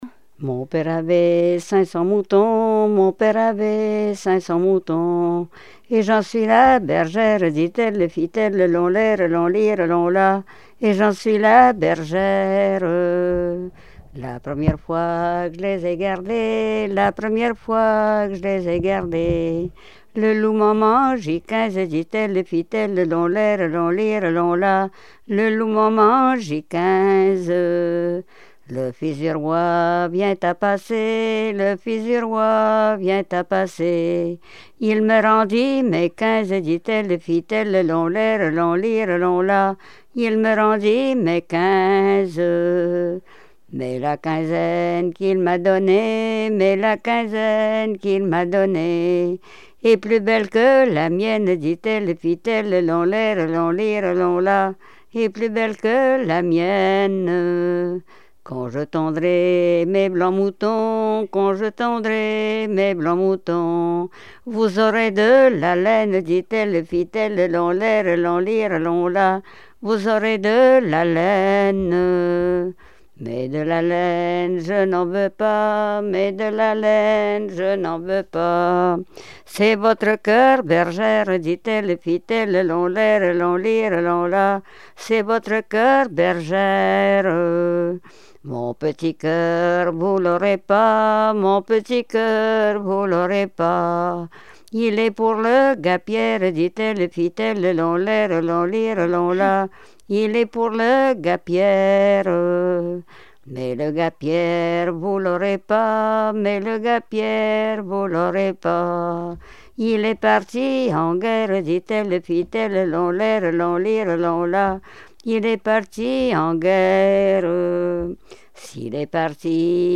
Genre laisse
Répertoire de chansons traditionnelles et populaires
Pièce musicale inédite